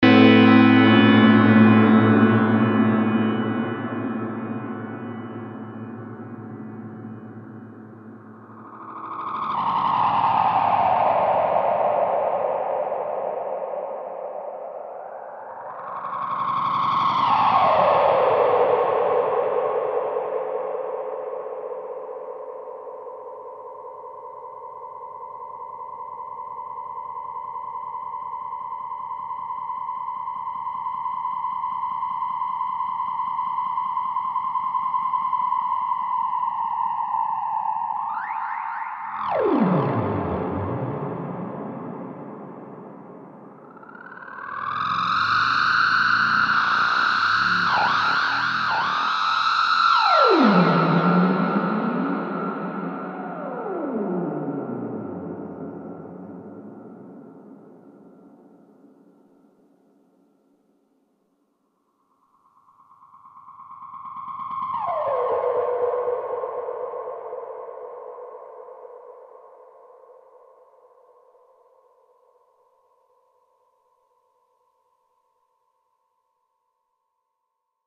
J'ai une simple question je sais bien que ca n'est pas du full analog , mais est ce qu'il est possible de s'en servir pour le faire osciller et pitcher les notes en jouant avec le potard time ?!
c'est ma vieille AD9 , mais je ne supporte plus son switch , il marche bien mais j'arrive jamais a appuyer dessus comme il faut ! je veux un vrai switch !
DELIRE DELAY.mp3